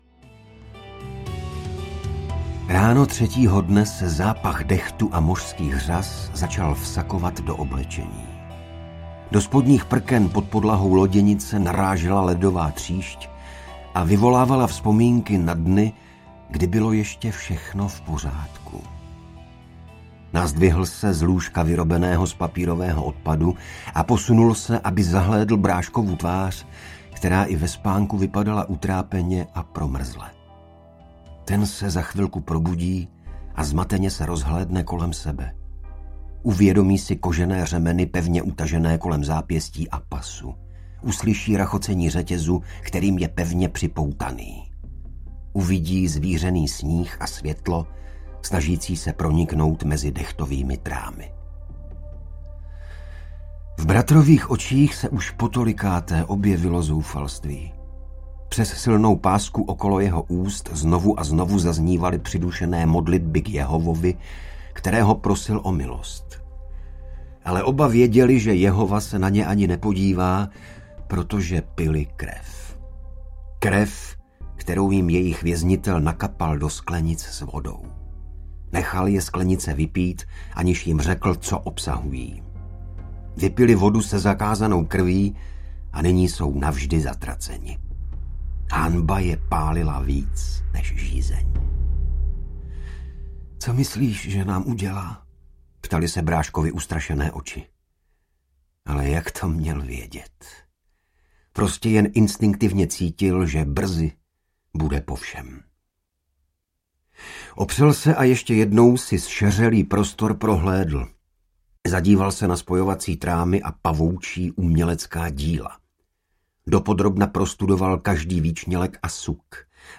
Ukázka z knihy
• InterpretIgor Bareš